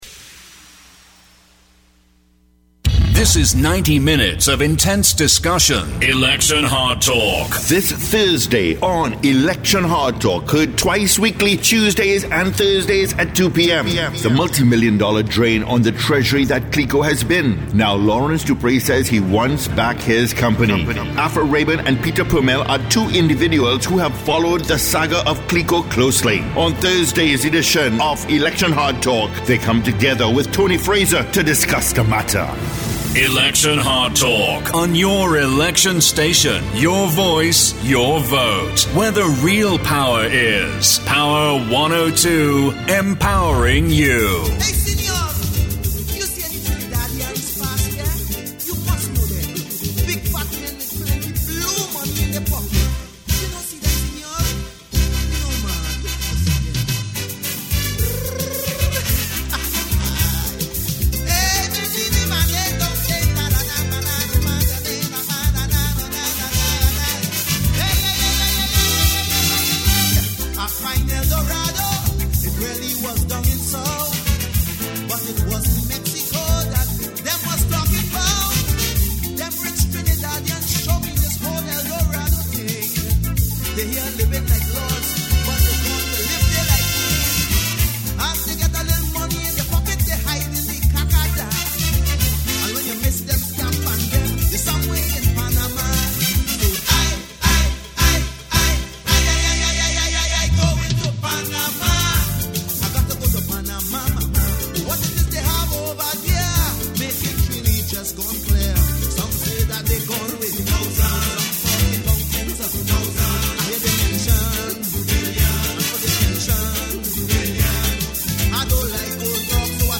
AUDIO: Election Hardtalk interview on Power 102FM – 16 Jul 2015